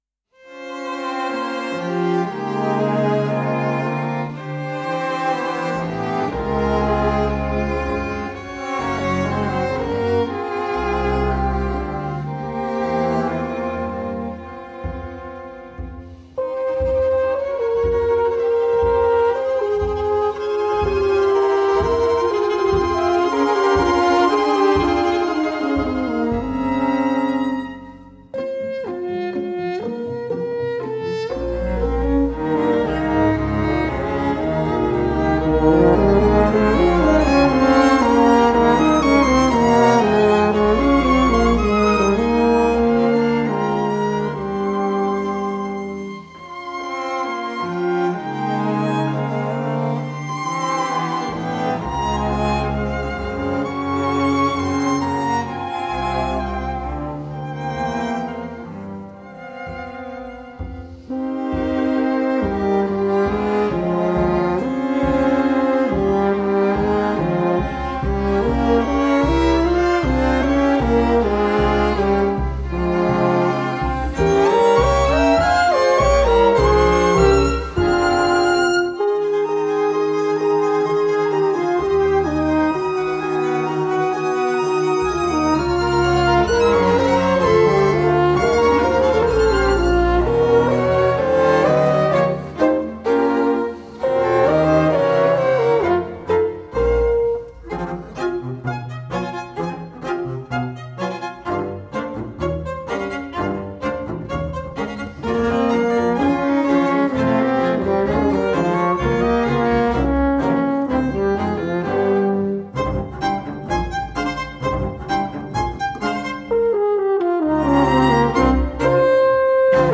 for horn and string quintet